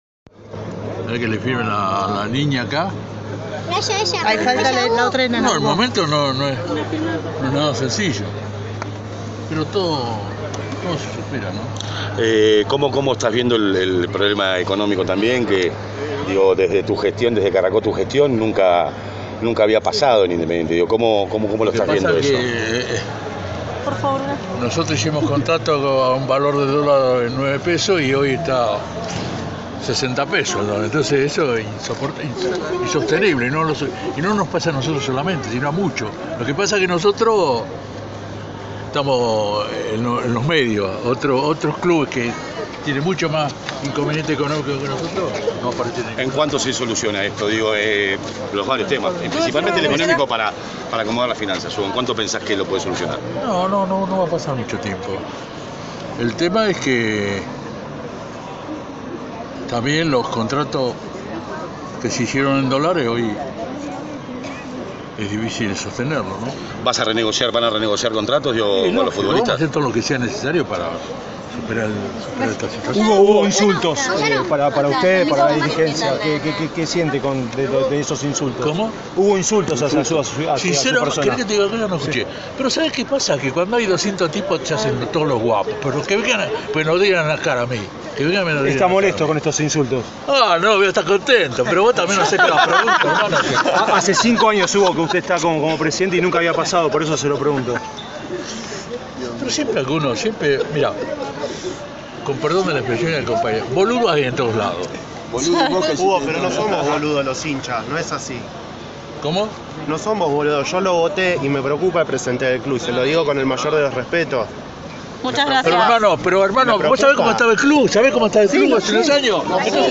AUDIO: Al Rojo vivo: tensión y duro cruce entre Hugo Moyano y un hincha
La tensión que se había vivido en la cancha, ni bien finalizó el partido, se instaló en la zona de camarines.
Y Moyano montó en cólera.